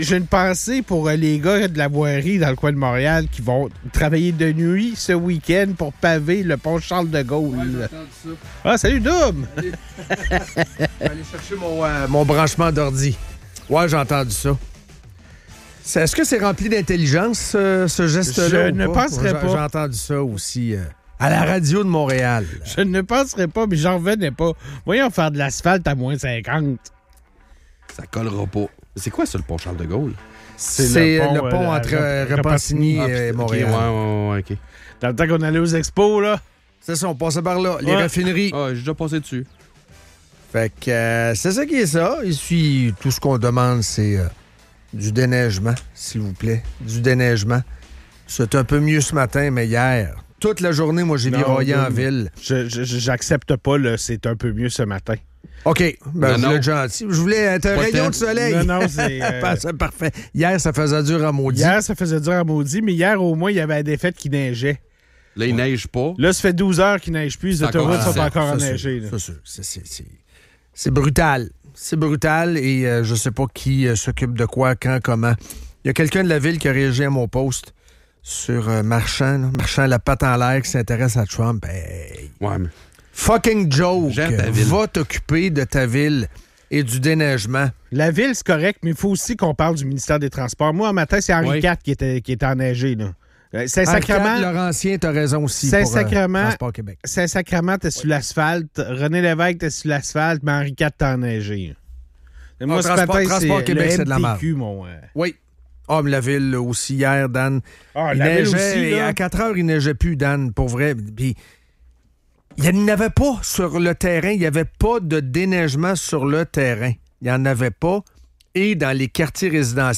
L'épisode aborde les préoccupations liées aux conditions de déneigement à Québec, soulignant l'inefficacité des services municipaux face à la neige accumulée. Les animateurs discutent aussi de la situation politique actuelle, notamment le projet de tramway et le manque de courage de la CAQ à aborder cette question de front.